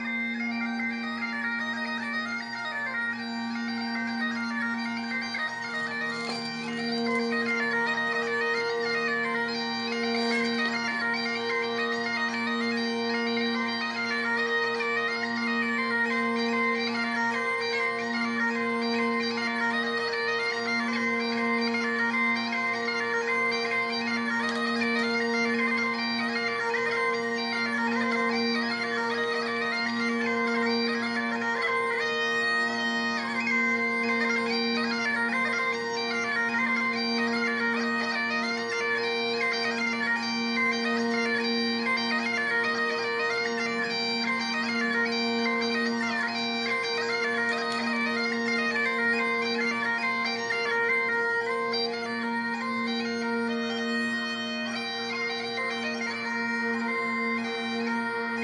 Bagpipes